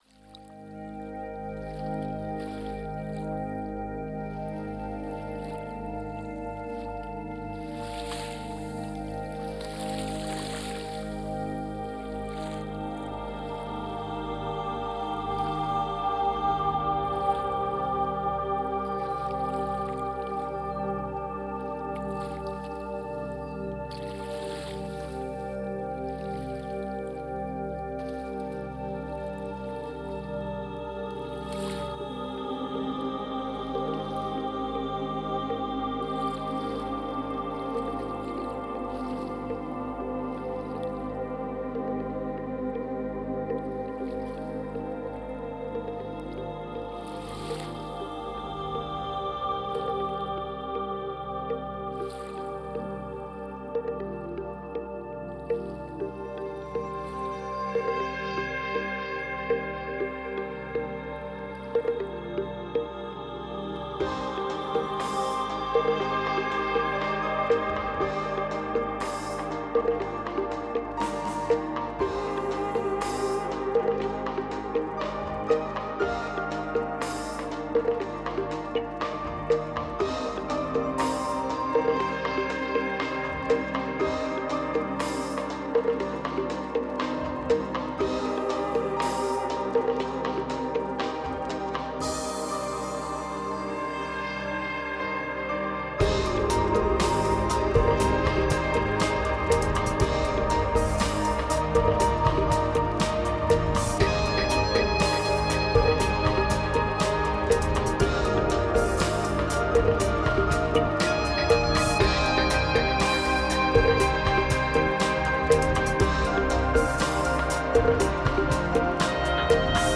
Genre: New Age, Enigmatic.